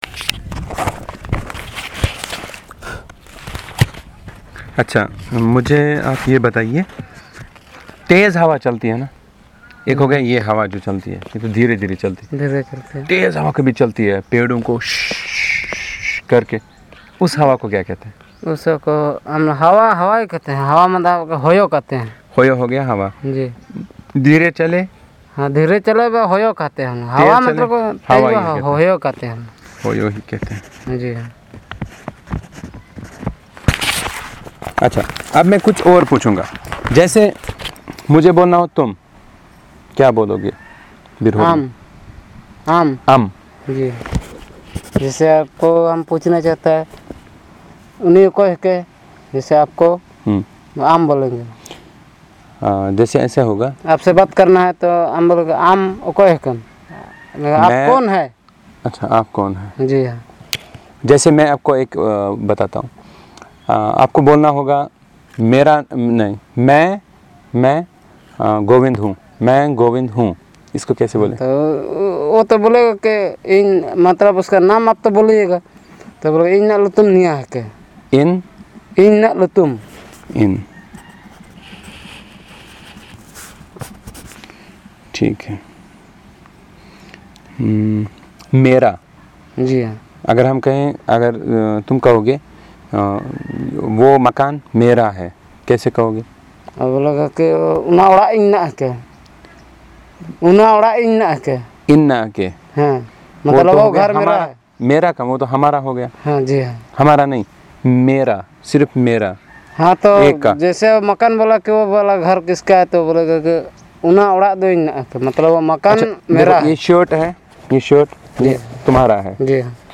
Elicitation of words related to weather and kinship